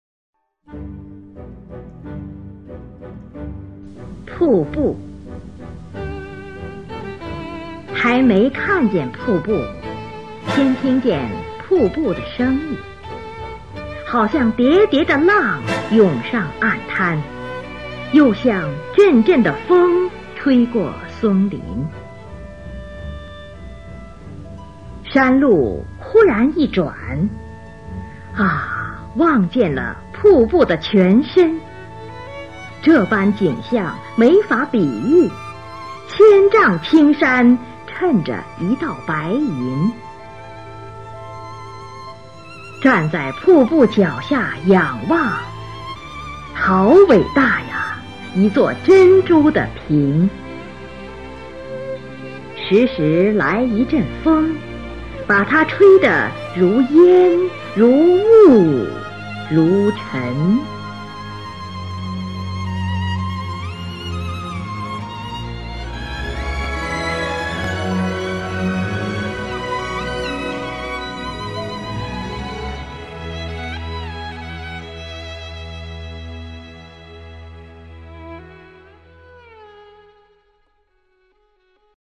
音乐，文字，图片。
[8/4/2014]中央人民广播电台著名播音员林如朗诵《瀑布》